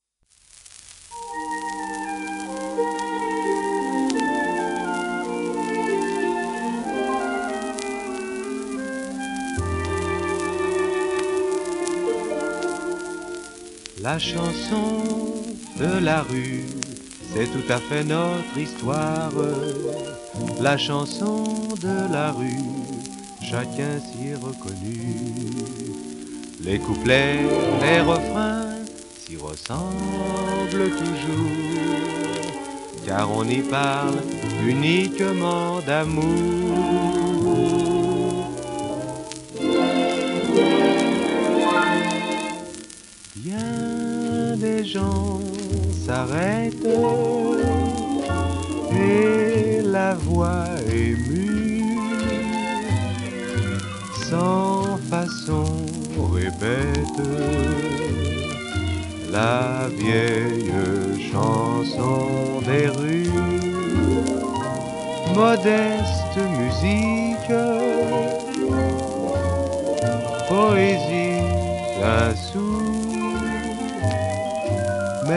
w/オーケストラ
SP時代を代表するシャンソン歌手の1人